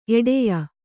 ♪ eḍeya